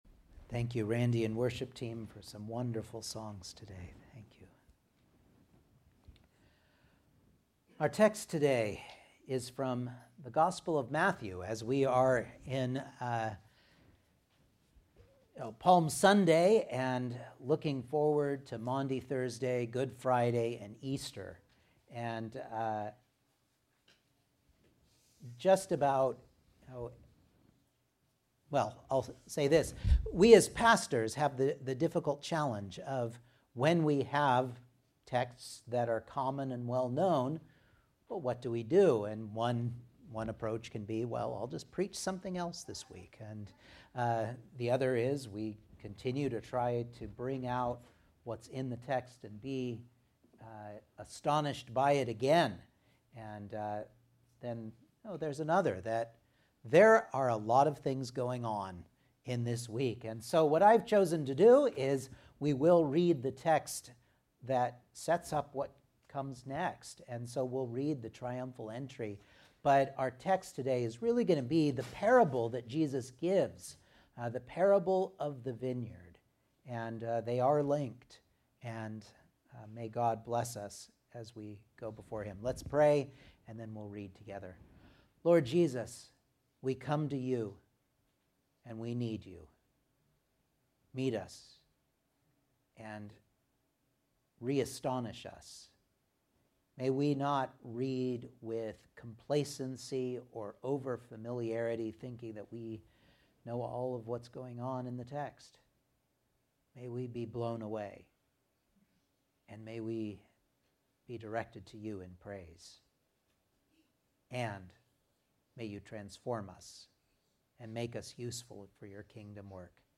Matthew 21:1-12,33-46 Service Type: Sunday Morning Outline